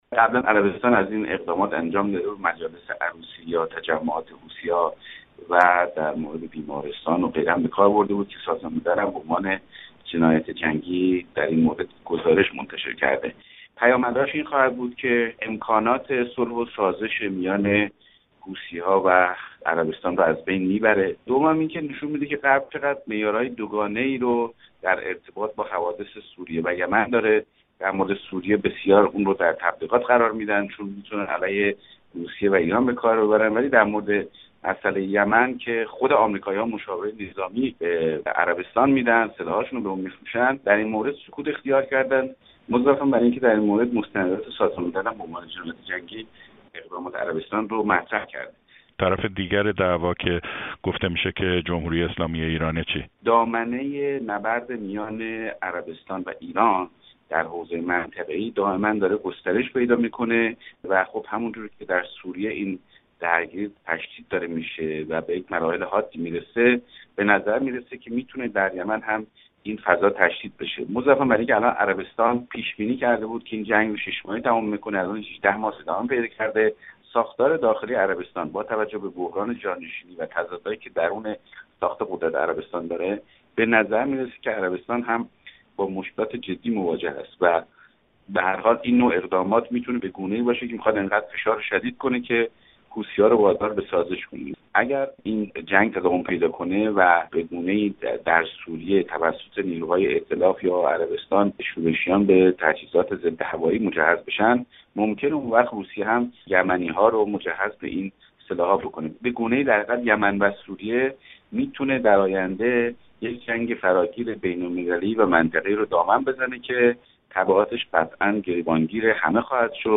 درباره پیامدهای احتمالی این رویداد به پرسش‌های رادیو فردا پاسخ داده است.